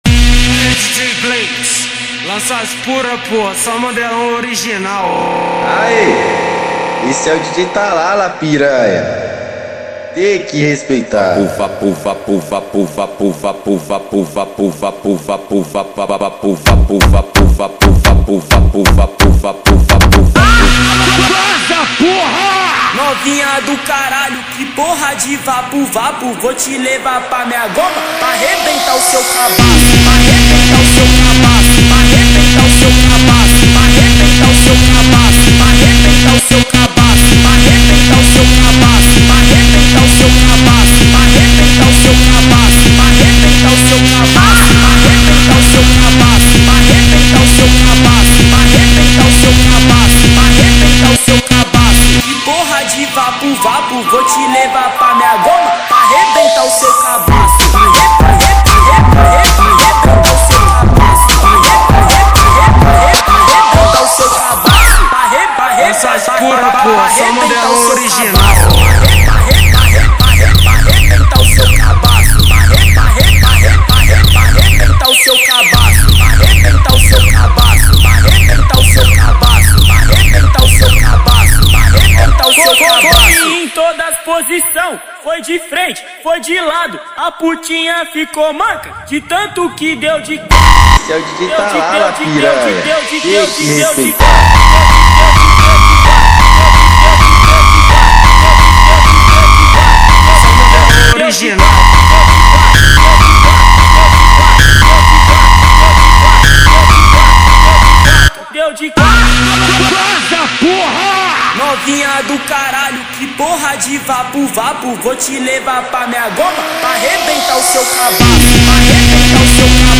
Gênero: MPB